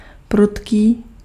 Ääntäminen
Ääntäminen US : IPA : [swɪft]